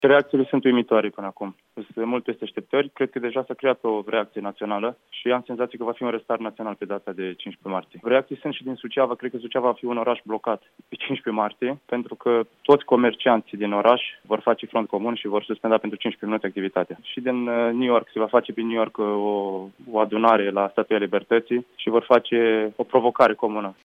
care într-un interviu acordat Europa FM